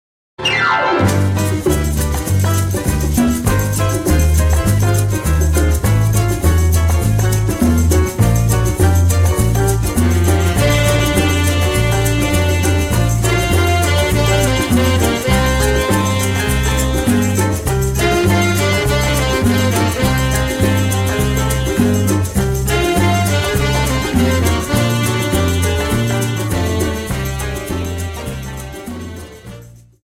Samba 50 Song